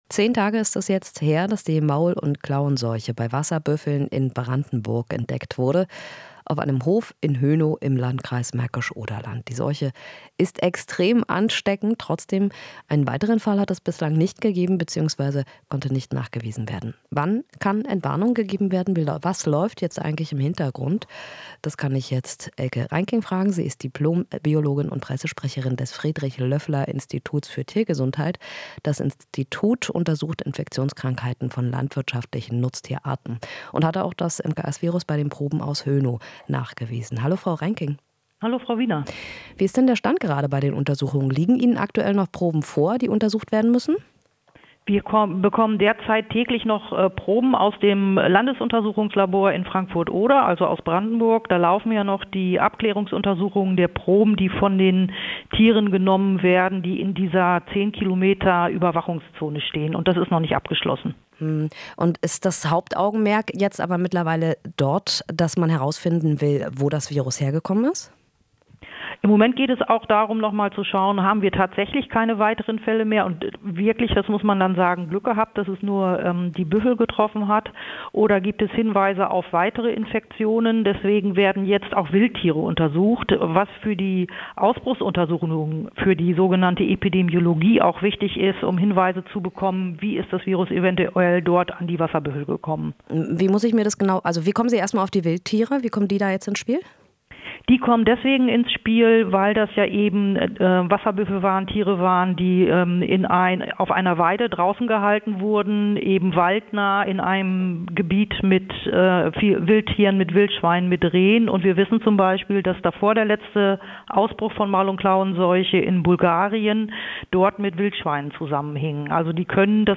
Interview - Maul- und Klauenseuche: Wann gibt es Entwarnung?